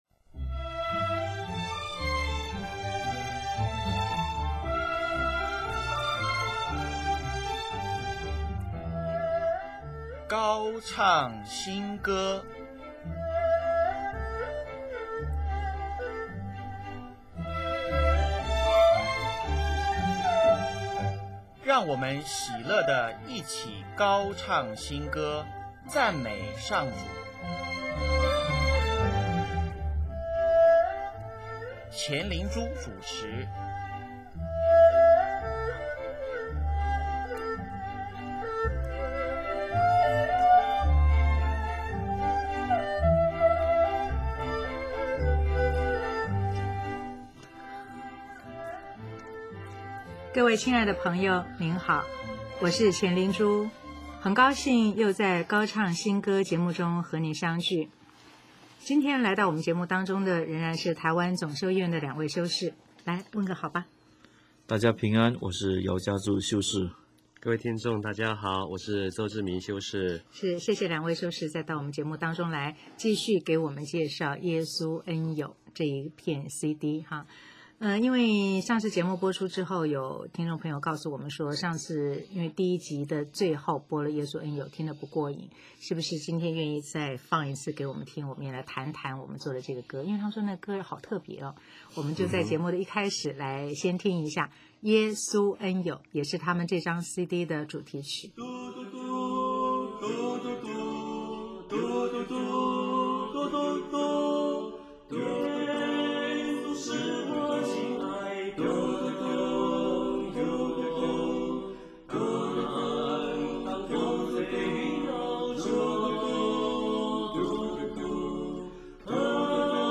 “耶稣恩友”採用无伴奏的唱法，加入爵士味道，用轻快的方式唱歌，让人体会到，耶稣不是严肃的朋友，他也是很开朗的。